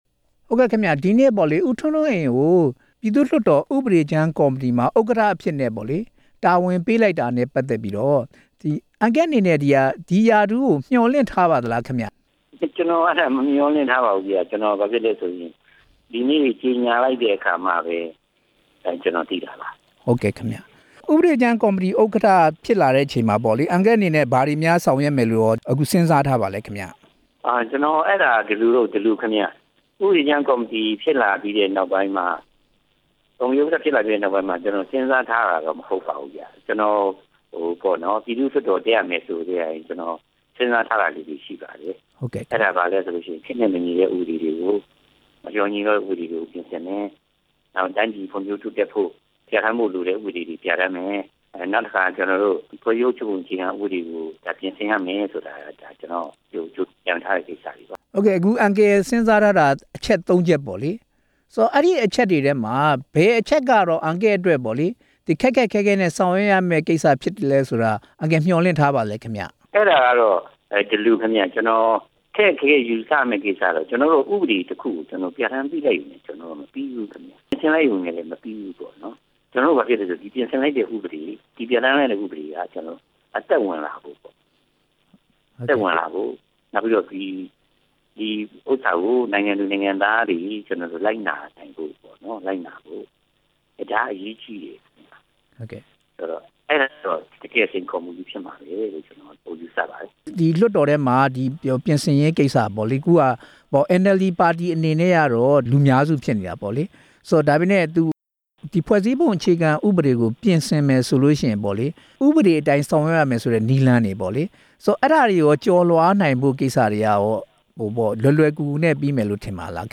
ပုဒ်မ ၅၉ (စ) ဆိုင်းငံ့ရေး ဥပဒေ ကြမ်းကော်မတီဥက္ကဌနဲ့ မေးမြန်းချက်